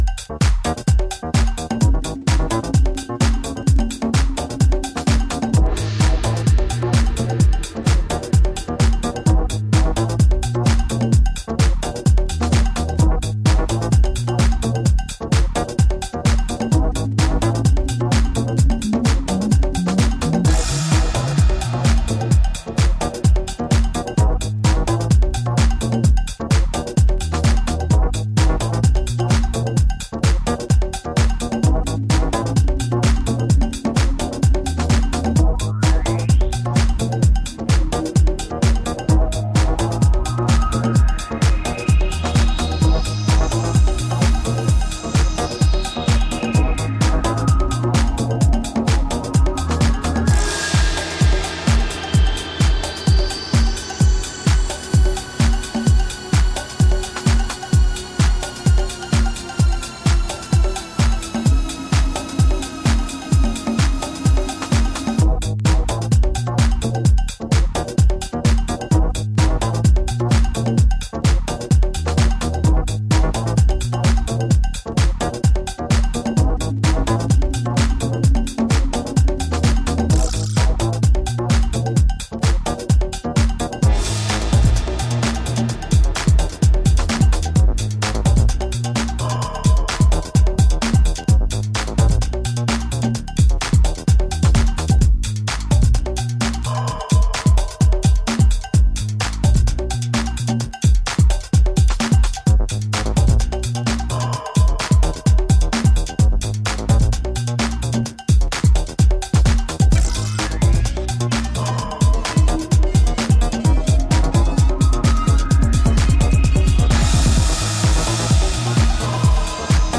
ブレイク明け昇天必至なトランシーさマシマシの3トラック。